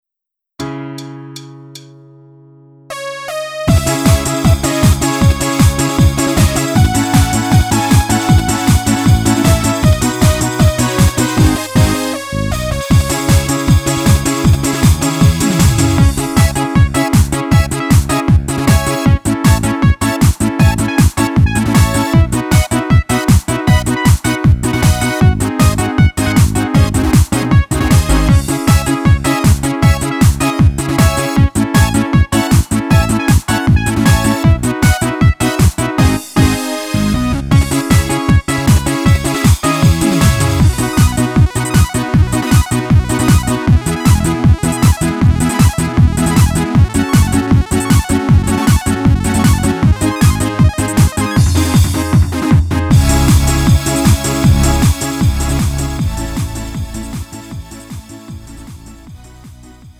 음정 -1키 3:37
장르 구분 Lite MR